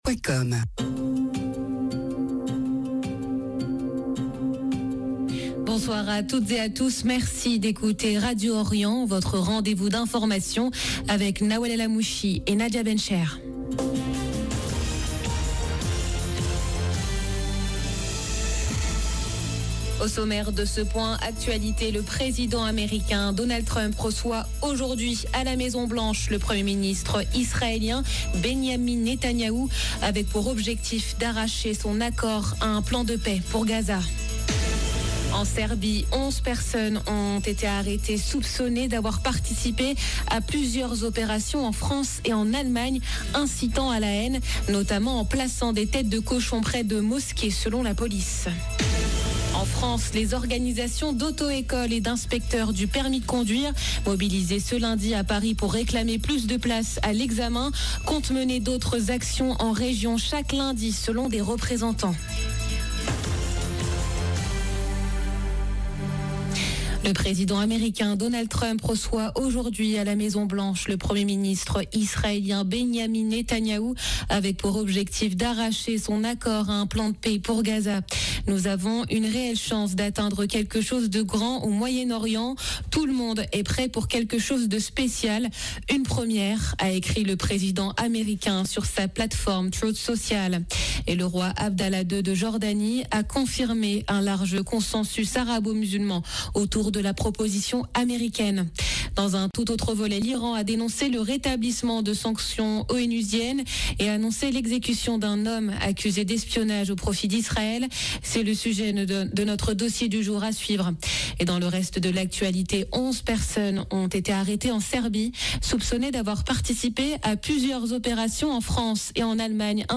Journal de 17H du 29 septembre 2025